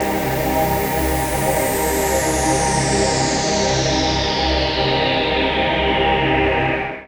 VOICEPAD01-LR.wav